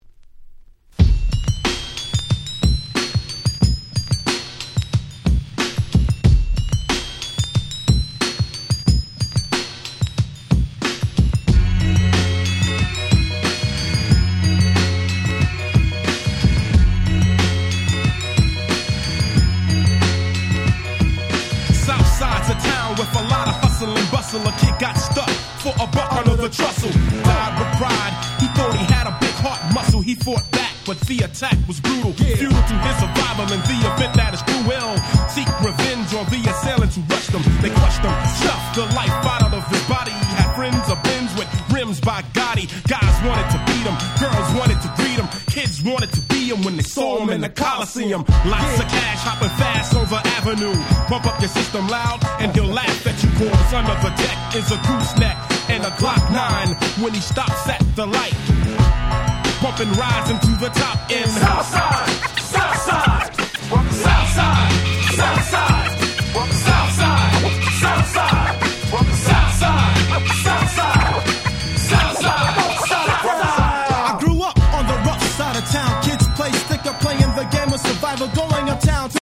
90's Hip Hop Classics !!
90's Boom Bap ブーンバップ